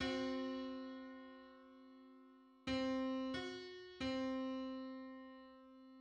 Public domain Public domain false false This media depicts a musical interval outside of a specific musical context.
Seven-hundred-thirteenth_harmonic_on_C.mid.mp3